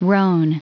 Prononciation du mot roan en anglais (fichier audio)
Prononciation du mot : roan